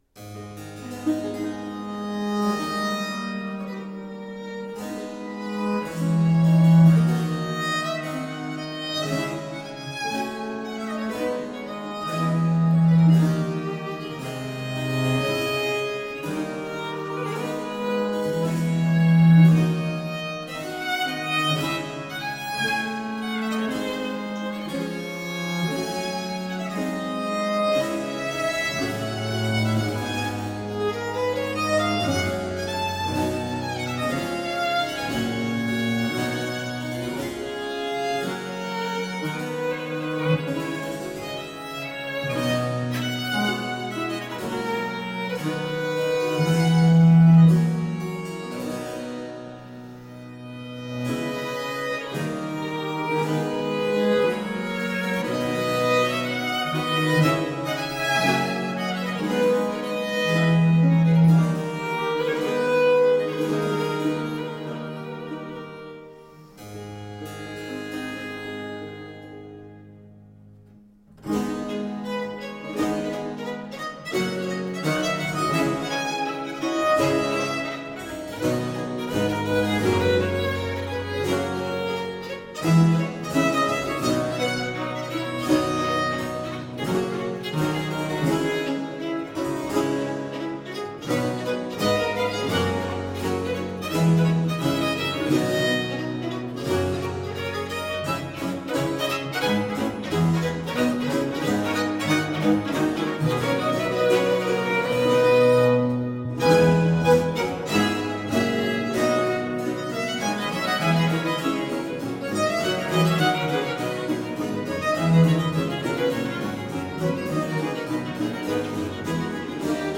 Rare and extraordinary music of the baroque.
using period instruments